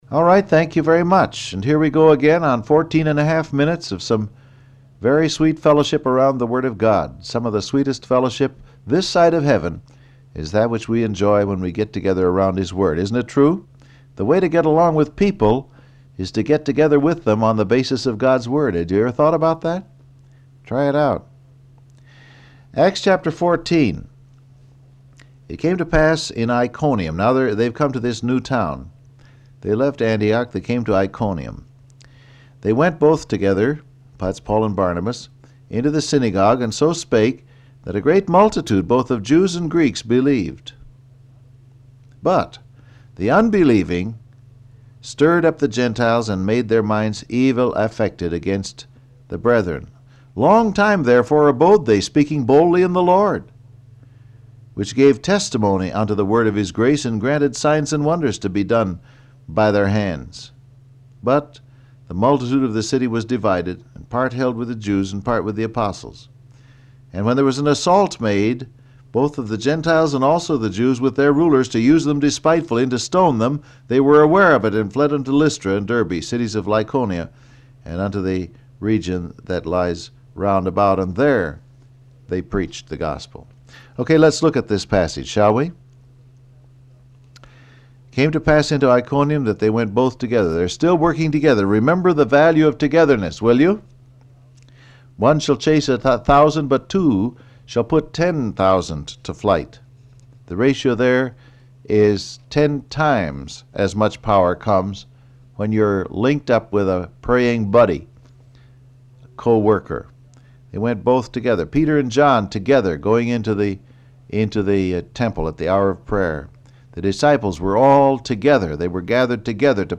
Download Audio Print Broadcast #7418 Scripture: Acts 14:1-2 Topics: Together , Spirit Filled , Contagious Unbelief Transcript Facebook Twitter WhatsApp Alright, thank you very much.